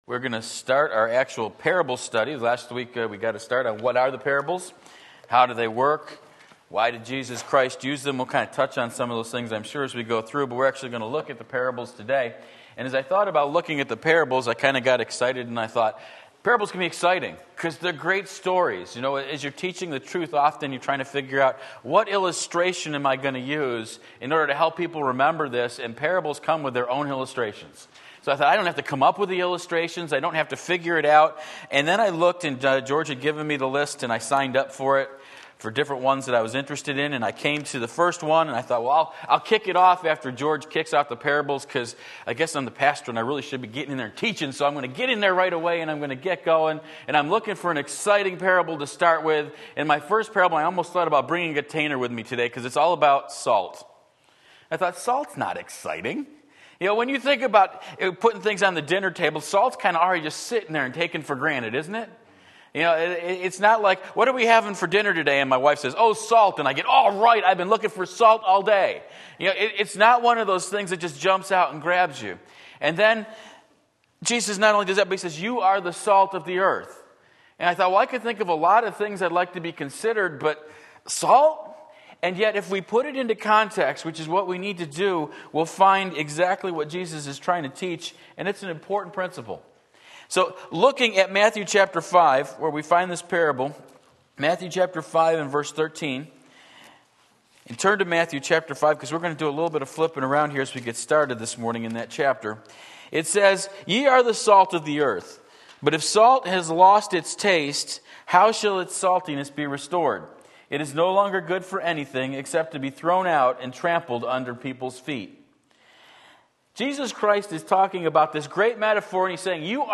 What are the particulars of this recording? Salt and Its Savor Matthew 5:13 Sunday School